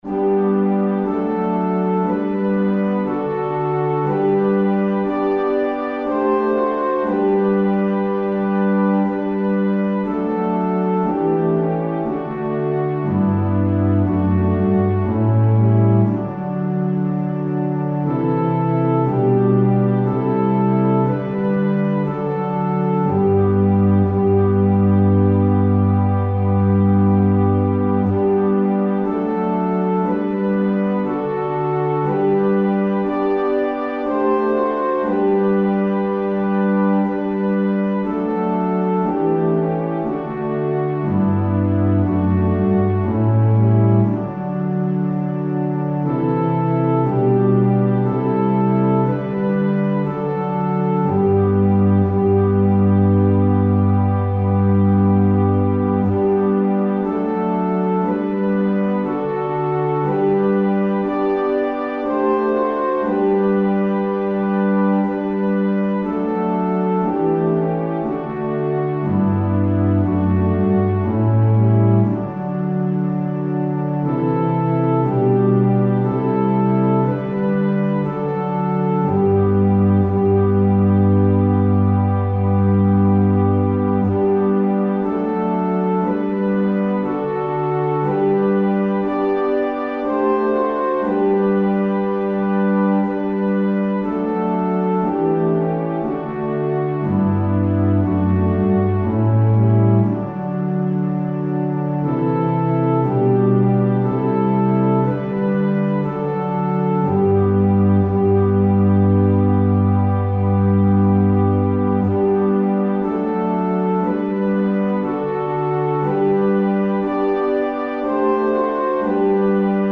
Click the Button to sing the prayer (key for Women), or play the song in a New Window